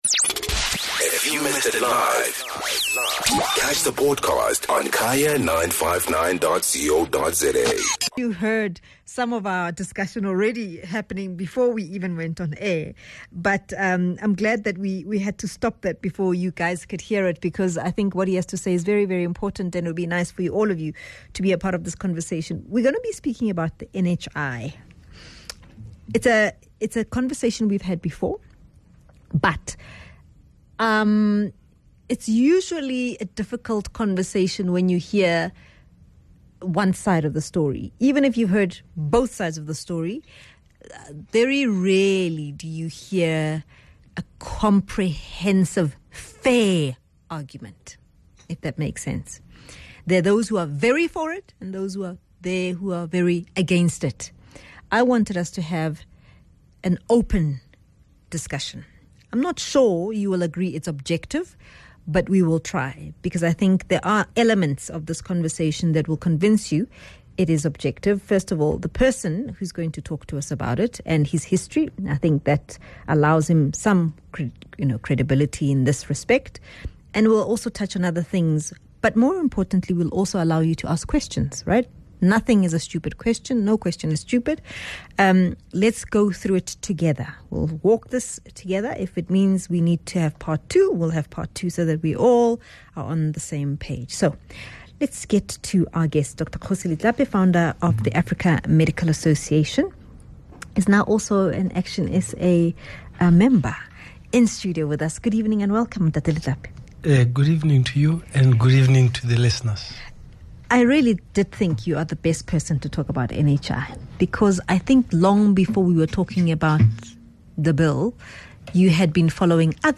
9 Dec Discussion: NHI Bill